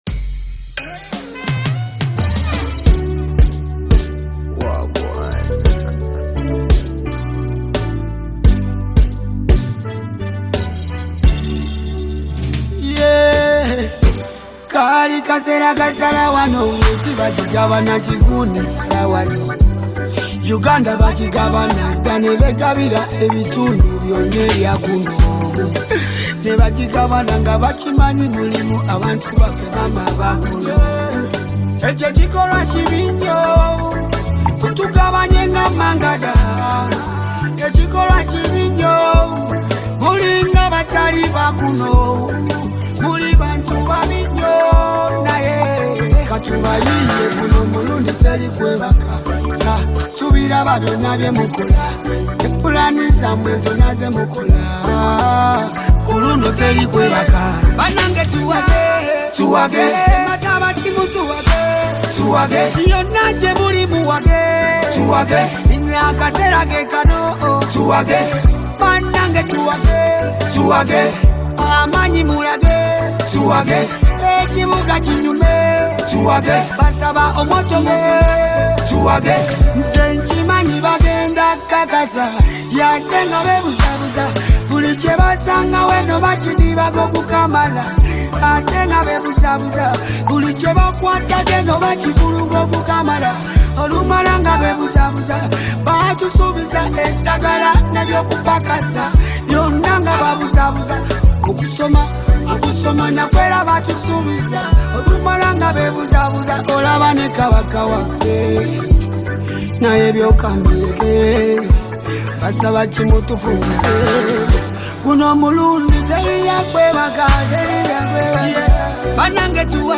smooth and emotional track
With his soulful voice and touching lyrics